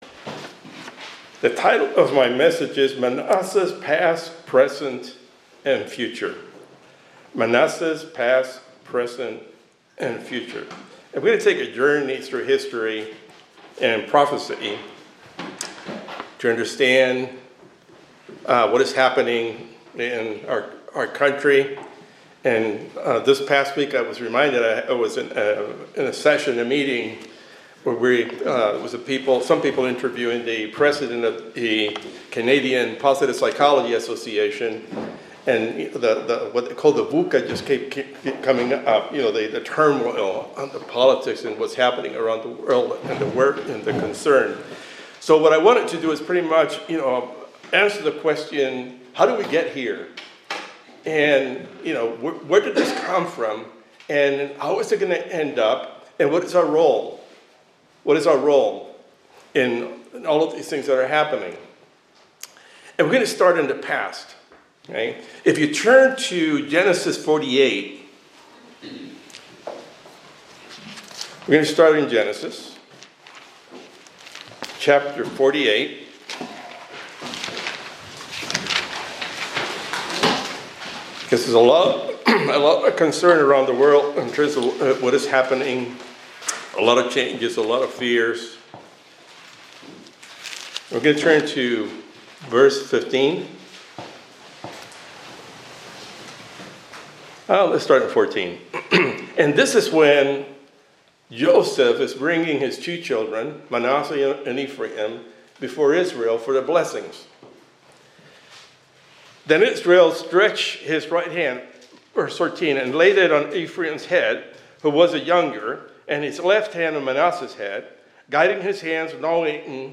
This sermon focuses on the themes of past, present, and future, particularly in relation to the biblical figure Manasseh and the current state of the United States.